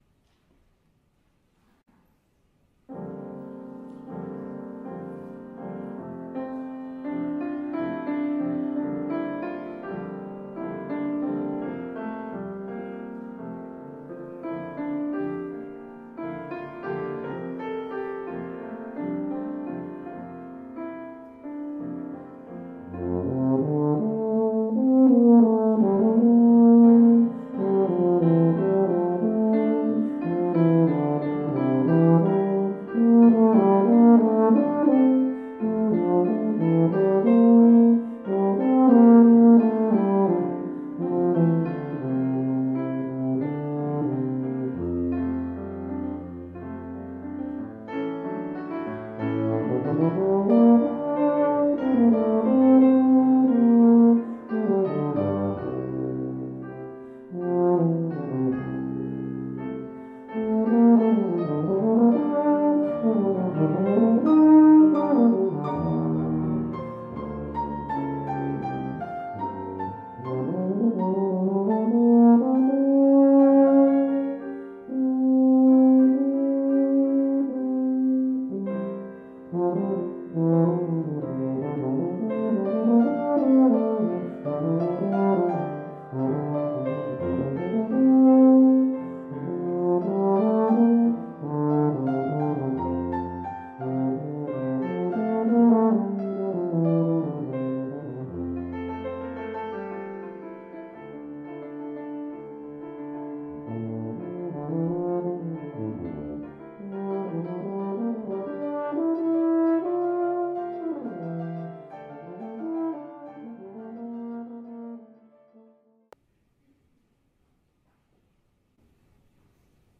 Voicing: Tuba Solo